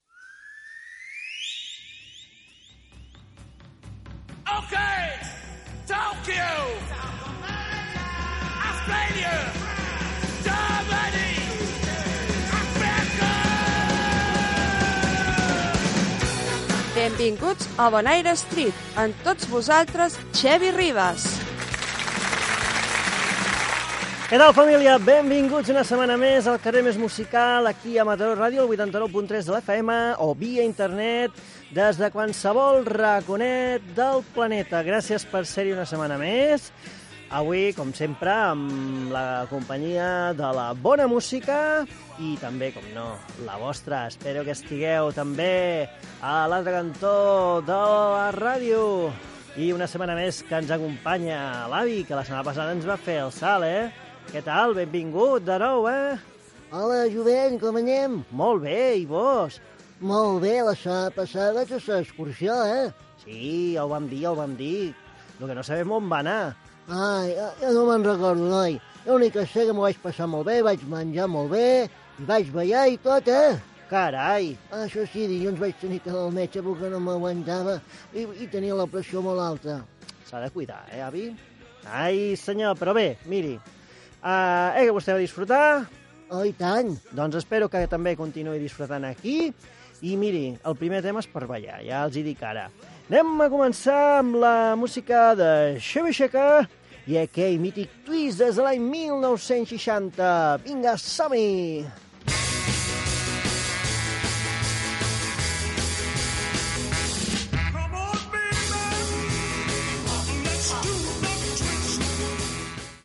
Careta del programa, identificació de l'emissora, presentació, diàleg amb el personatge de l'avi i tema musical
Musical